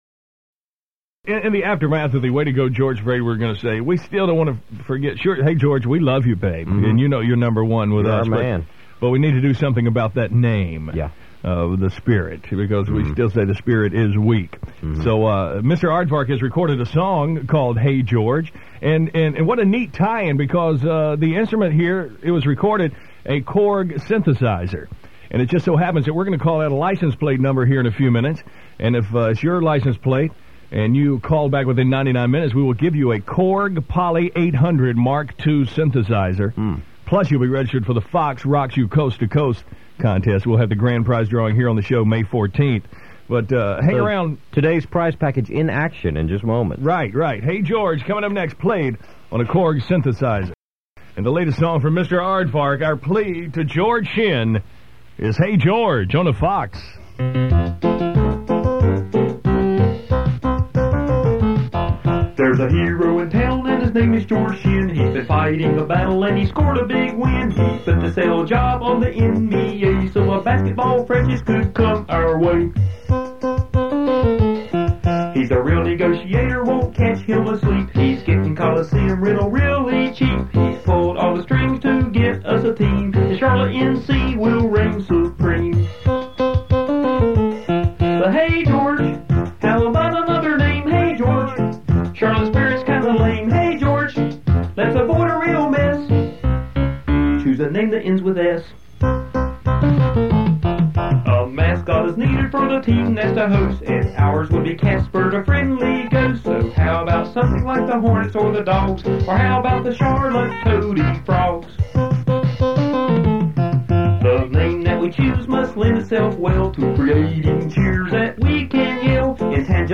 On-the-air version (file size 2,740,517 bytes)